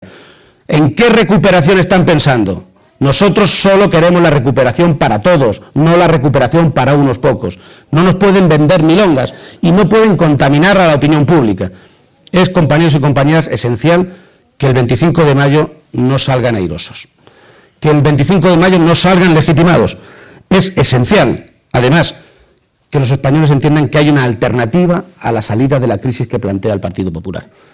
El secretario general del PSOE de Castilla-La Mancha, Emiliano García-Page, ha aprovechado que hoy ofrecía un mitin en la provincia de Cuenca para contestar algunas de las cosas que dijo Cospedal en el acto que celebró el pasado domingo en la capital conquense y señalar que votar al PSOE para que pierda el PP es hacerlo también contra los recortes y la mentira.